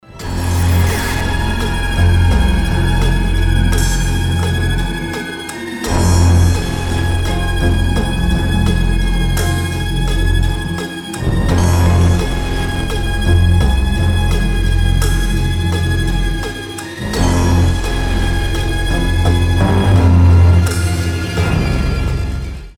пугающие
страшные
жуткие